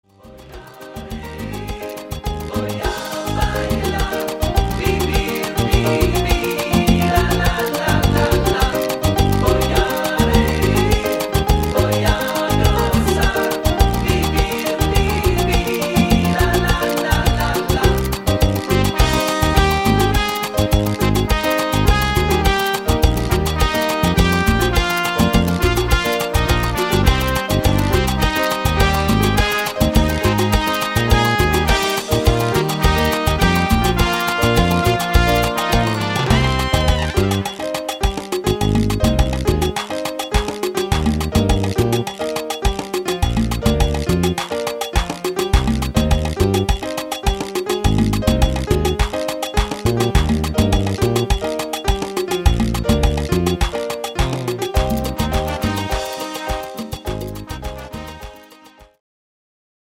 Salsa-Tanzschulen erprobt